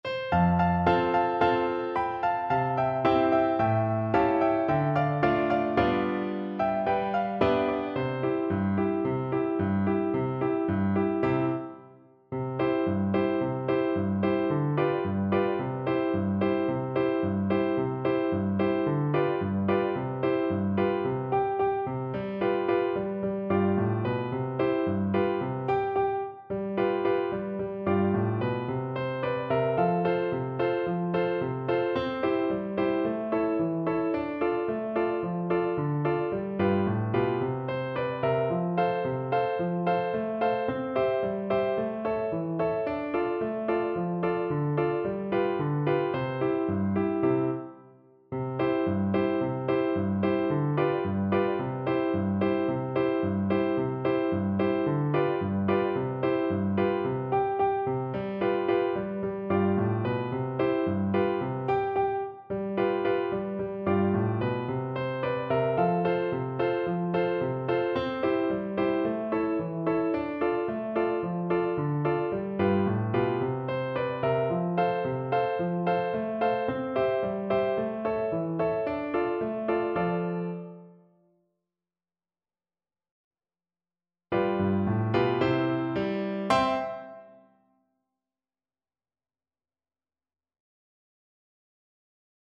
Traditional Music of unknown author.
C major (Sounding Pitch) (View more C major Music for Flute )
Allegro =c.110 (View more music marked Allegro)
2/4 (View more 2/4 Music)
Classical (View more Classical Flute Music)